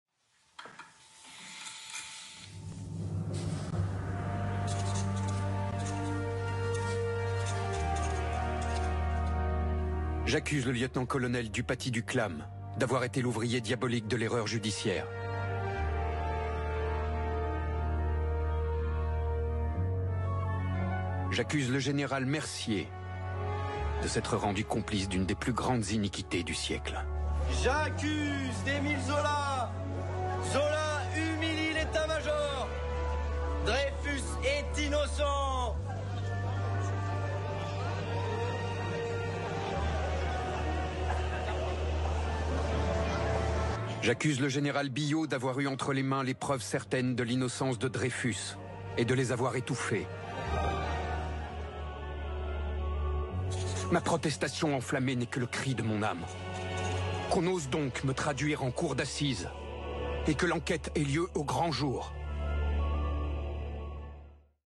Voix Off Fiction série "Les Zolas" France 2